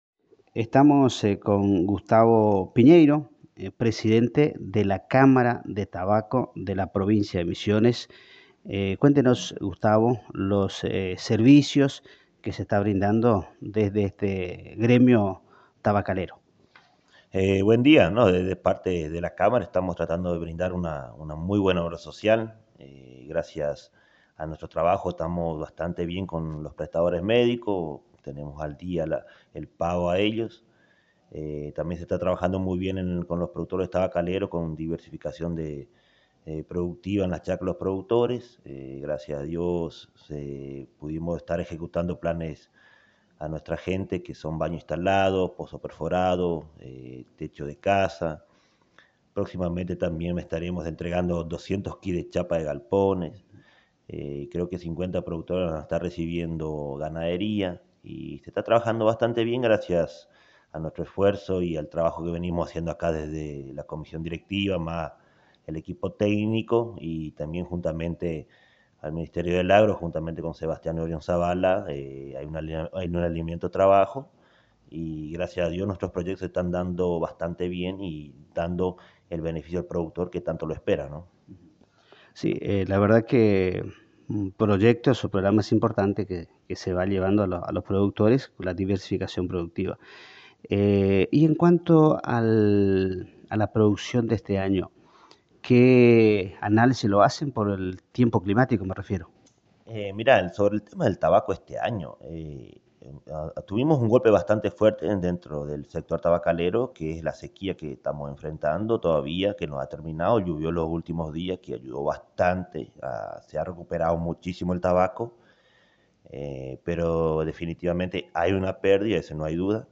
En una charla exclusiva para la Agencia de Noticias Guacurarí